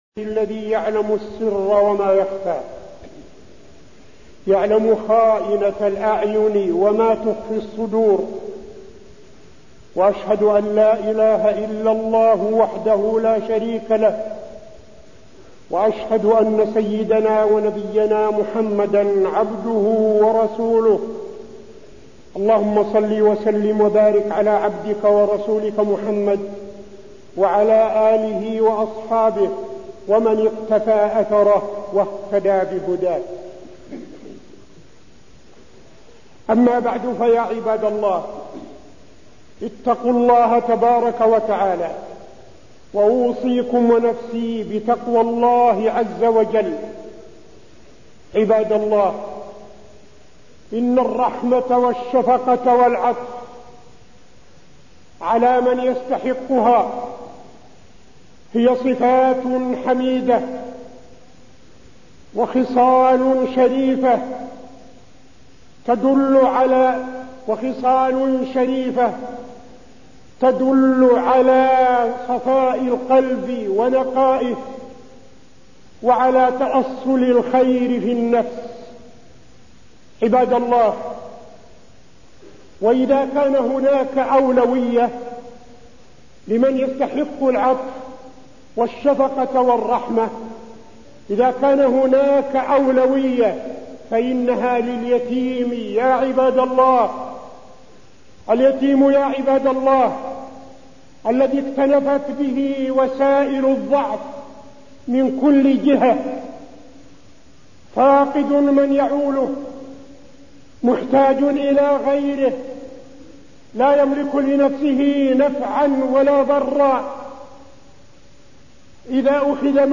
تاريخ النشر ١٤ جمادى الأولى ١٤٠٦ هـ المكان: المسجد النبوي الشيخ: فضيلة الشيخ عبدالعزيز بن صالح فضيلة الشيخ عبدالعزيز بن صالح كفالة اليتيم